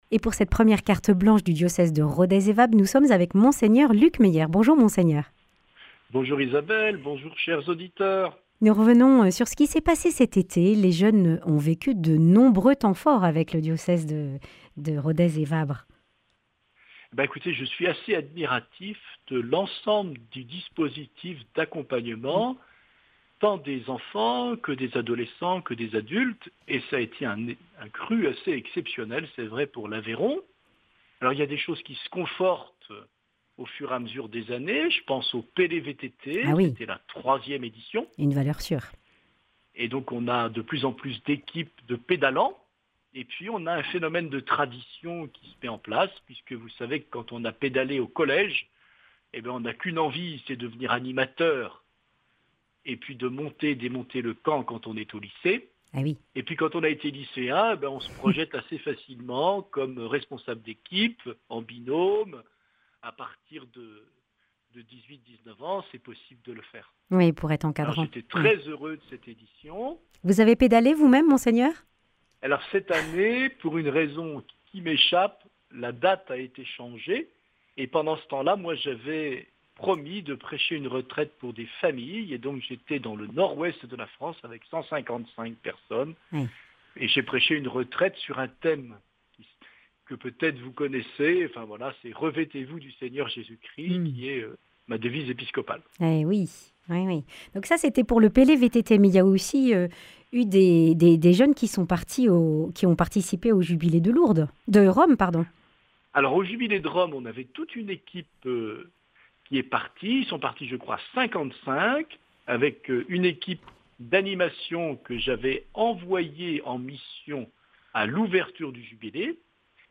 Partager Copier ce code (Ctrl+C) pour l'intégrer dans votre page : Commander sur CD Une émission présentée par Mgr Luc Meyer Evêque du diocèse de Rodez et Vabres Voir la grille des programmes Nous contacter Réagir à cette émission Cliquez ici Qui êtes-vous ?